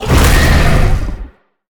Sfx_creature_hiddencroc_bite_02.ogg